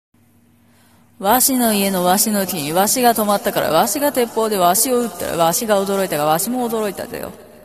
早口言葉